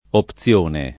[ op ZL1 ne ]